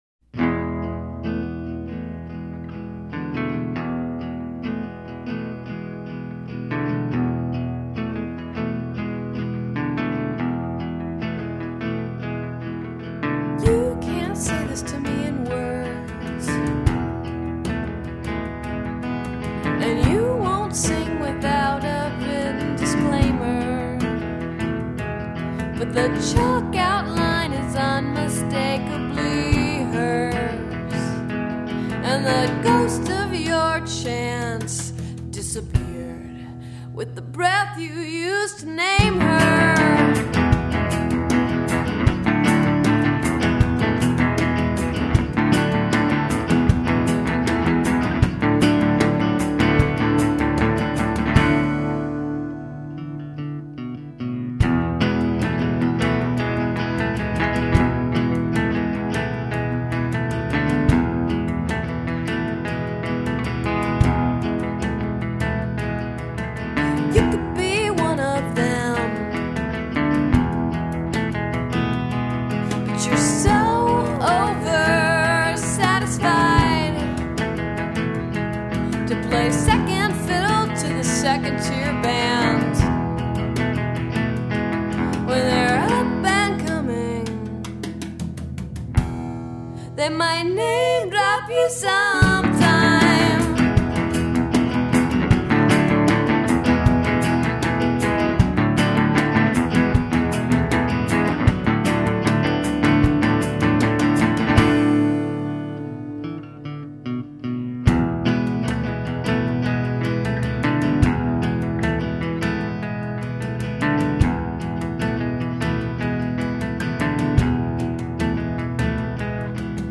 indie-rock